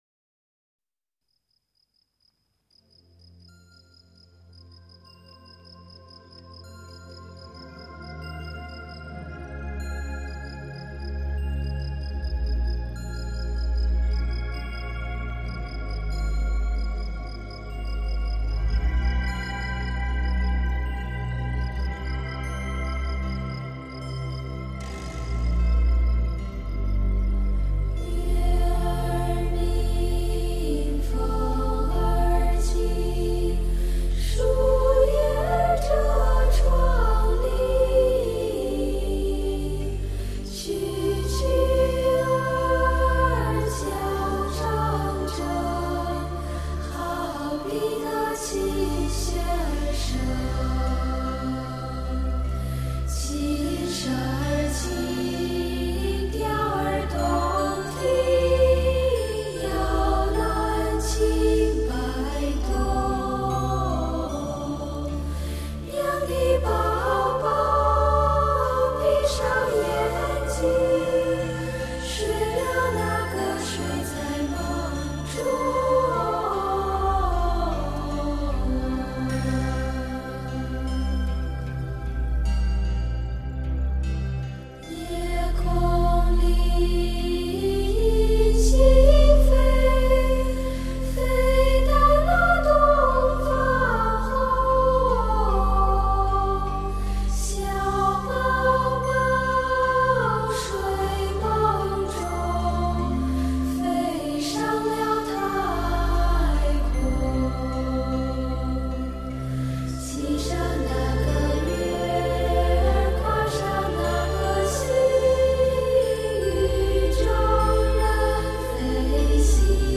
童声合唱是一种广受欢迎的形式，它包含错落的声部构成
与和谐悠扬的齐唱。